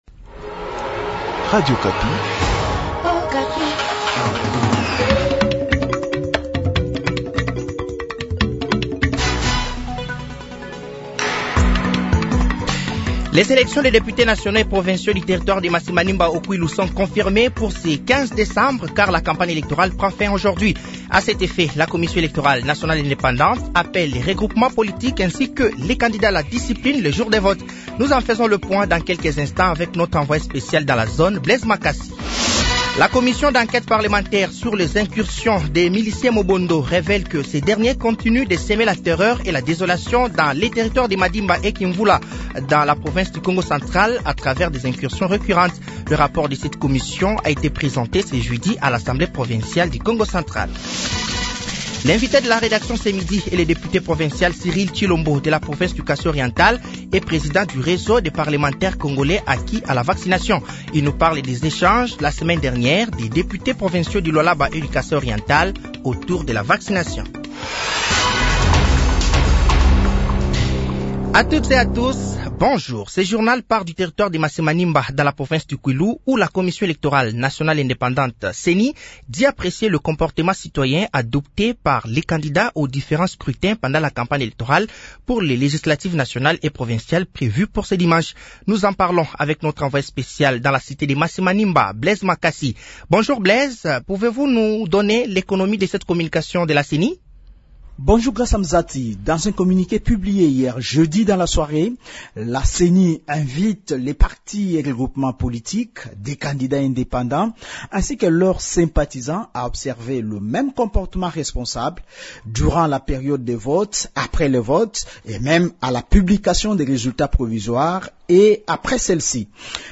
Journal francais de 12h de ce vendredi 13 decembre 2024